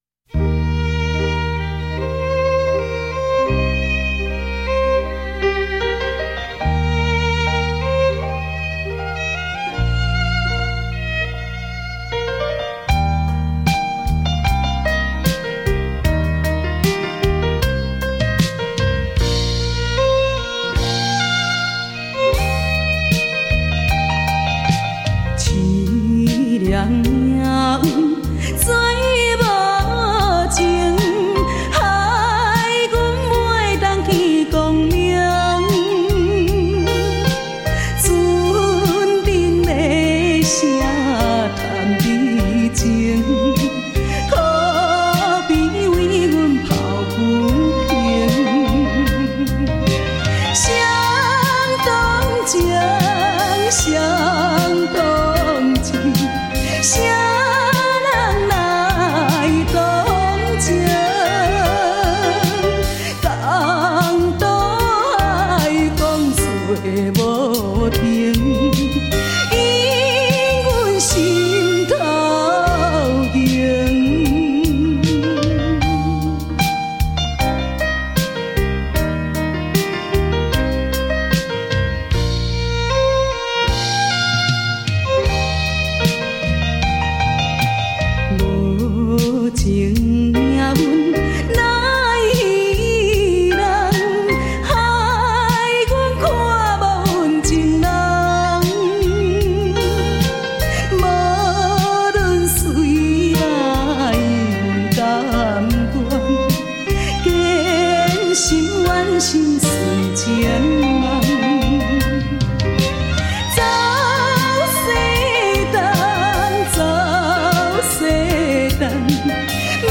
PIANO BAR